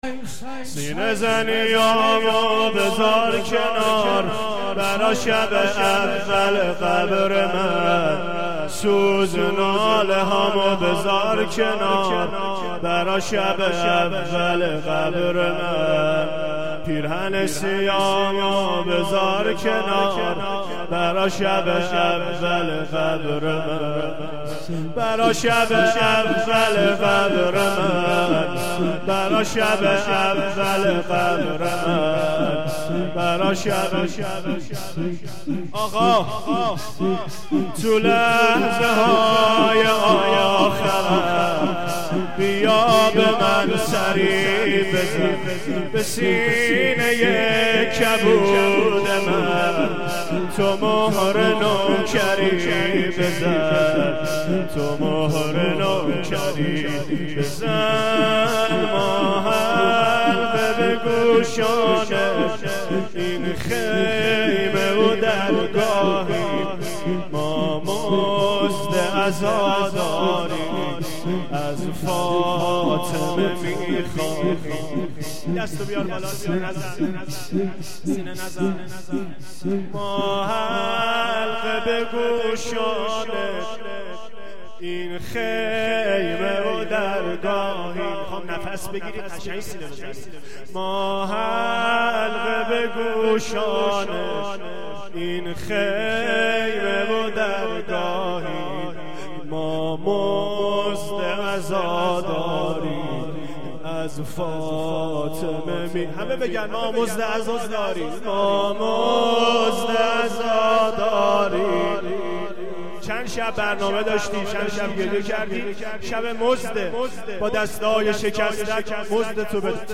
سینه زنی هامو بذار کنار براشب اول قبر من